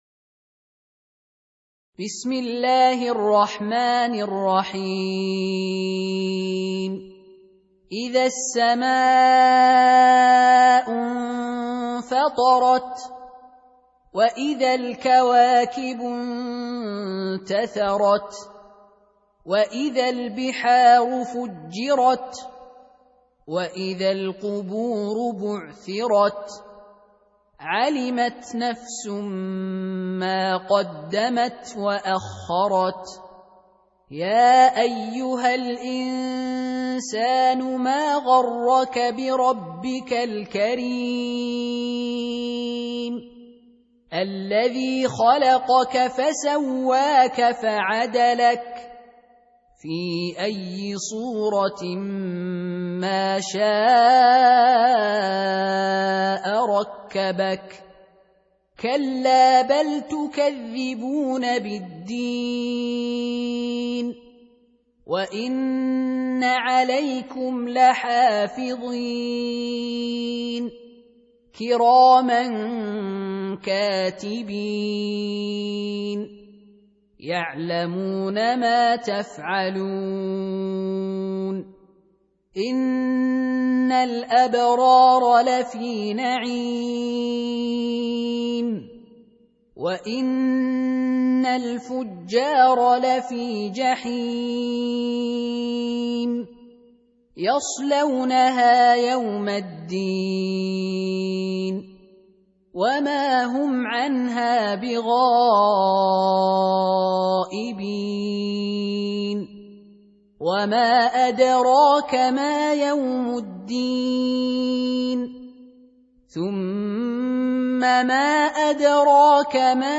Surah Repeating تكرار السورة Download Surah حمّل السورة Reciting Murattalah Audio for 82. Surah Al-Infit�r سورة الإنفطار N.B *Surah Includes Al-Basmalah Reciters Sequents تتابع التلاوات Reciters Repeats تكرار التلاوات